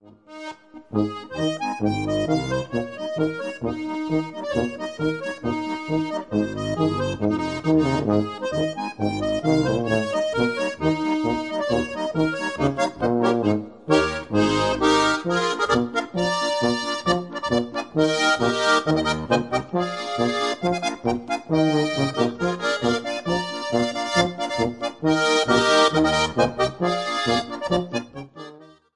Solo Accordion Recordings